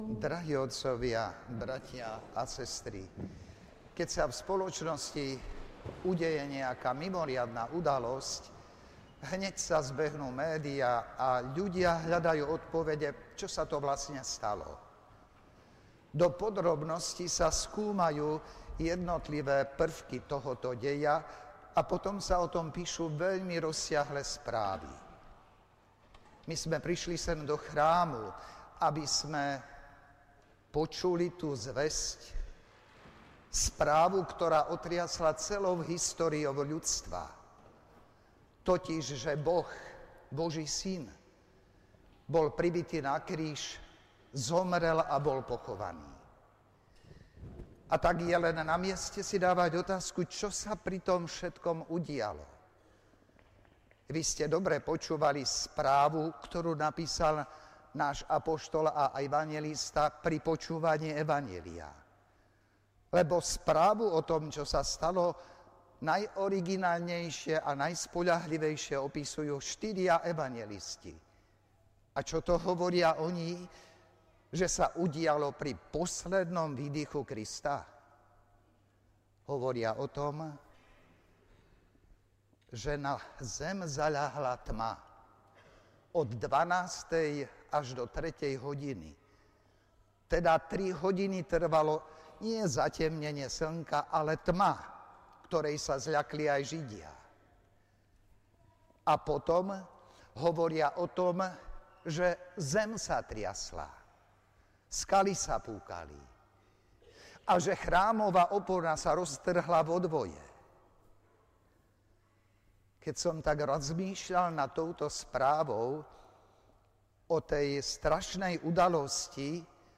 V závere sa prítomným prihovoril vladyka Milan Chautur, CSsR, ktorý celej slávnosti predsedal. Nadviazal aj už na spomínaný chválospev.